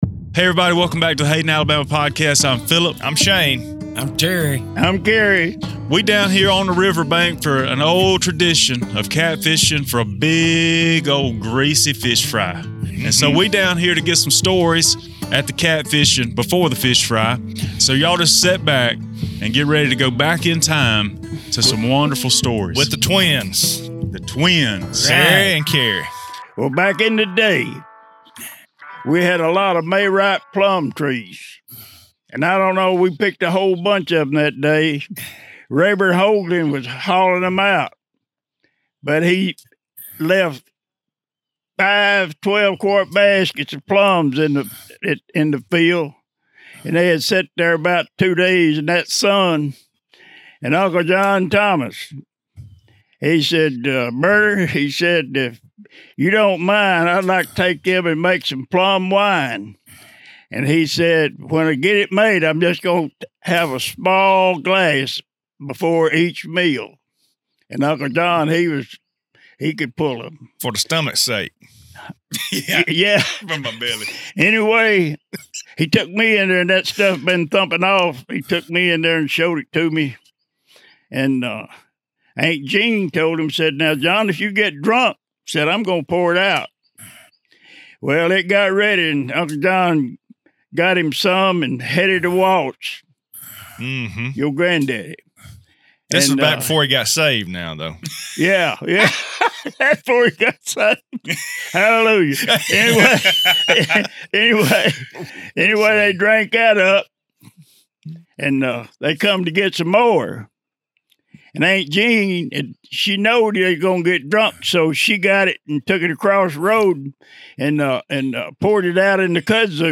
Slow down and sit a spell with us as some great stories are told about a time where story is the only vehicle to take you there. You are about to enjoy an impromptu storytelling on the bank of the Locust Fork River with some men who have lived a lot of life and love to share about it.